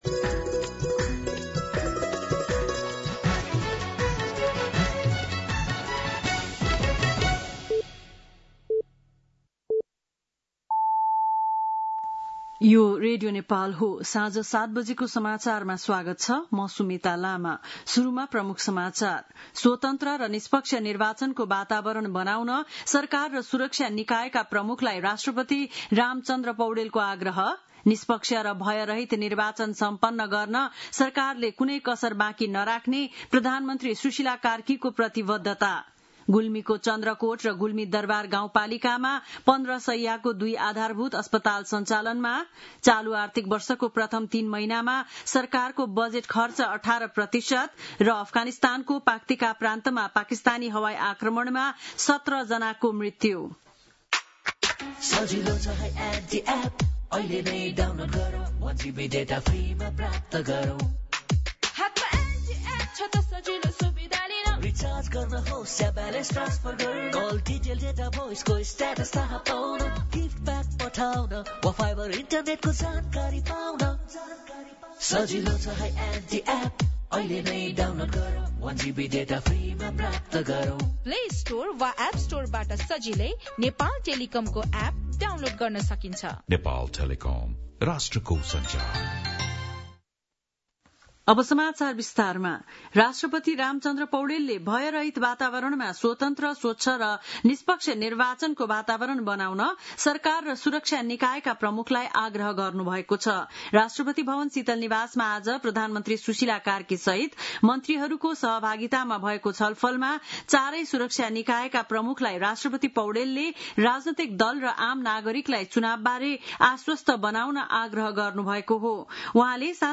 बेलुकी ७ बजेको नेपाली समाचार : १ कार्तिक , २०८२